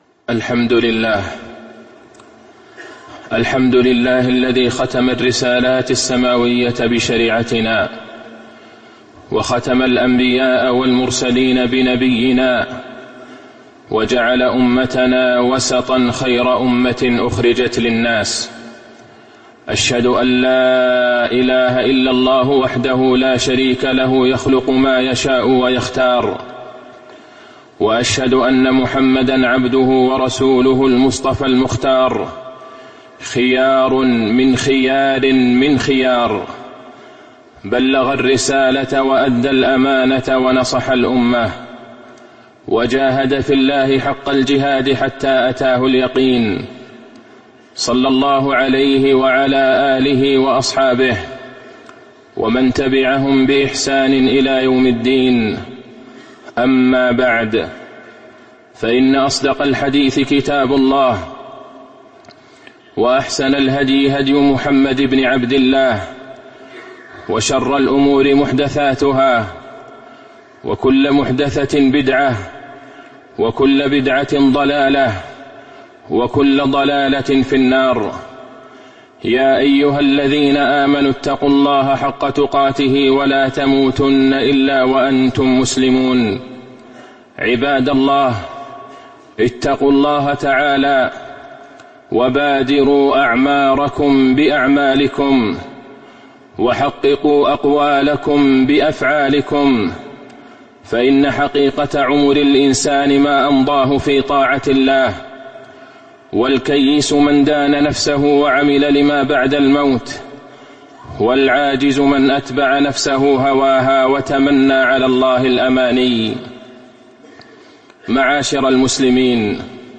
تاريخ النشر ١٩ ذو الحجة ١٤٤٤ هـ المكان: المسجد النبوي الشيخ: فضيلة الشيخ د. عبدالله بن عبدالرحمن البعيجان فضيلة الشيخ د. عبدالله بن عبدالرحمن البعيجان وماذا بعد الحج